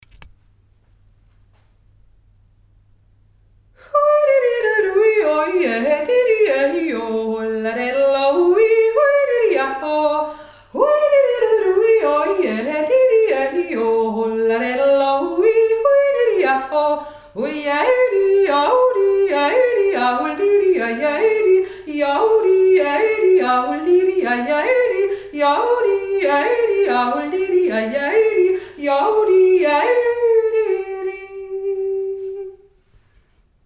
Frühlingserwachen 2017 Virgen Osttirol